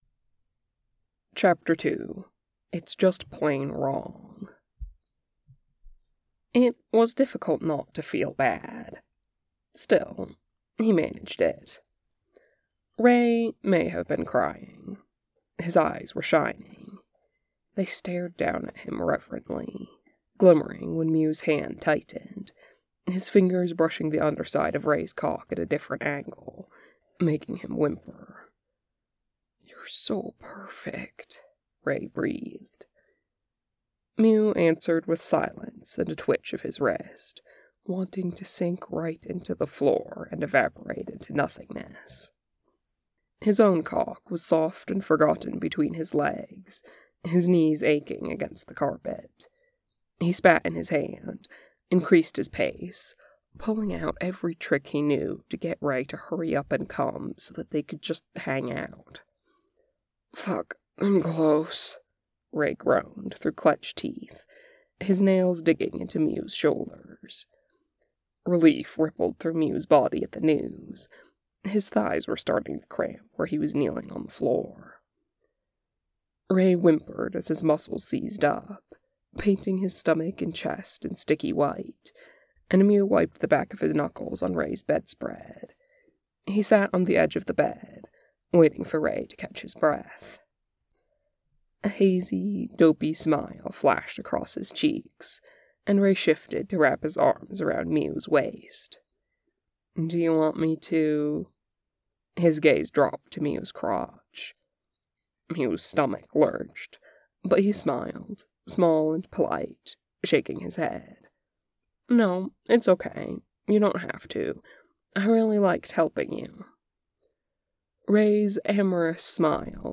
reader